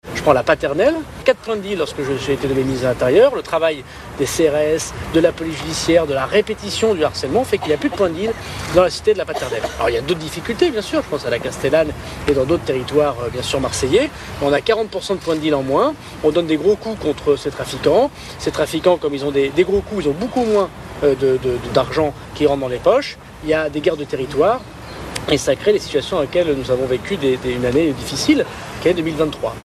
son-darmanin-marseille-78504.mp3